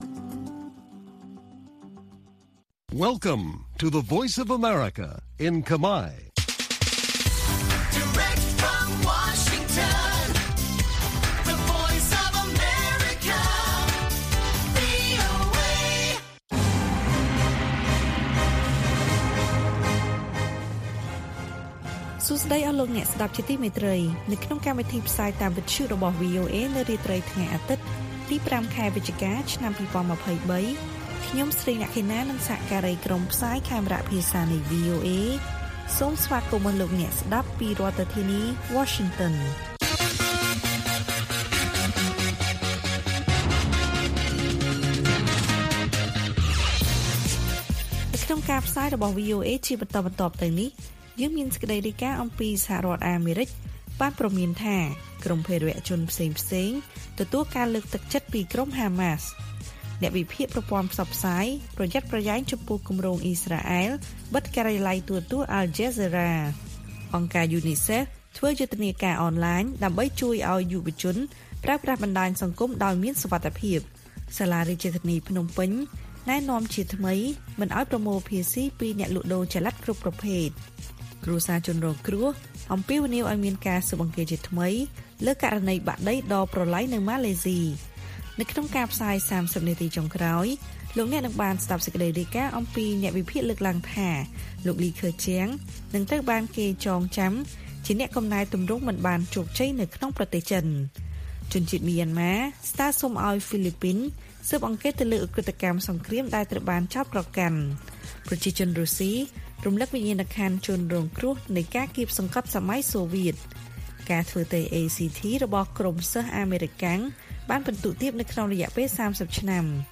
ព័ត៌មានពេលរាត្រី ៥ វិច្ឆិកា៖ អាមេរិកព្រមានថាក្រុមភេរវជនផ្សេងៗទទួលការលើកទឹកចិត្តពីក្រុមហាម៉ាស់